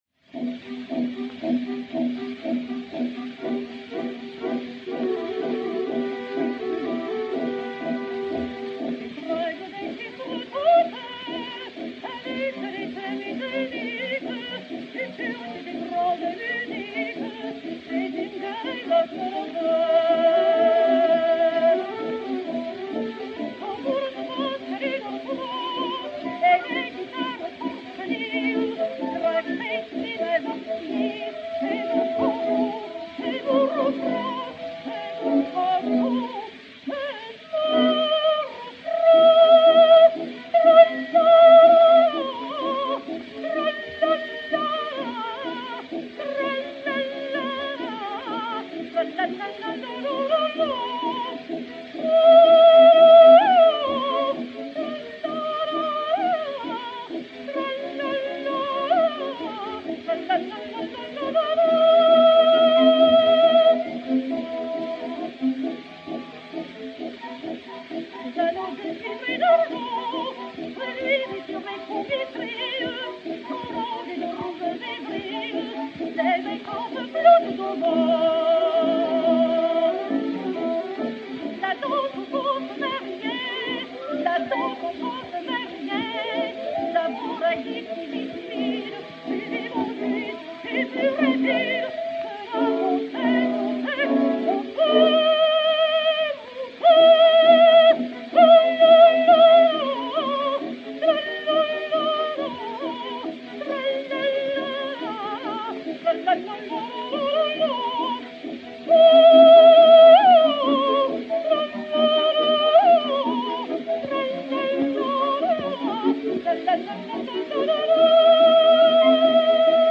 Emma Calvé (Carmen) et Orchestre
C6042-1, enr. aux USA le 20 mars 1908